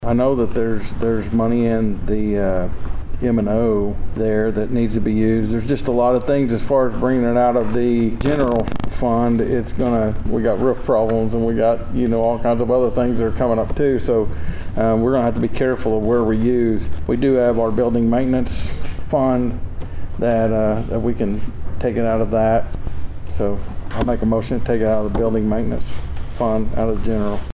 The Board of Osage County Commissioners convened for a regularly scheduled meeting at the fairgrounds on Monday morning.
District Two Commissioner Steve Talburt spoke on how he feels like this